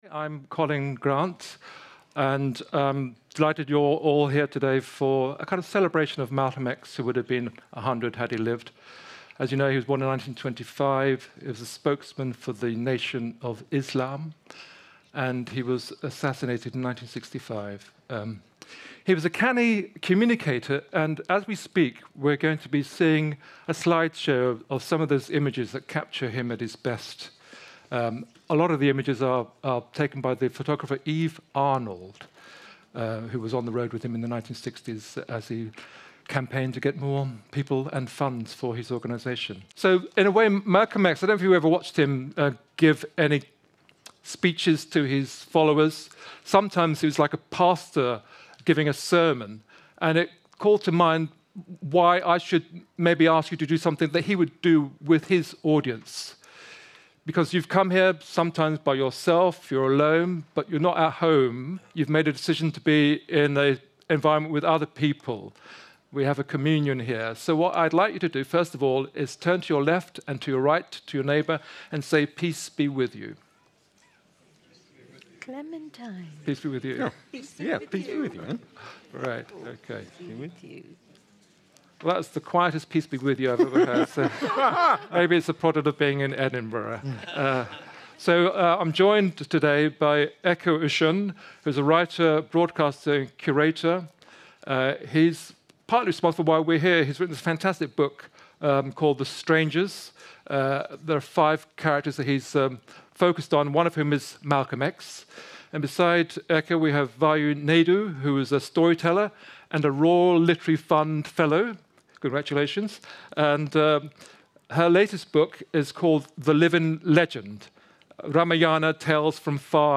At the Edinburgh Festival 2025, WritersMosaic delved into Malcom X's enduring role as a revolutionary and a symbol of defiance.
MalcolmX_Edinburgh_LiveEvent_Rtx.mp3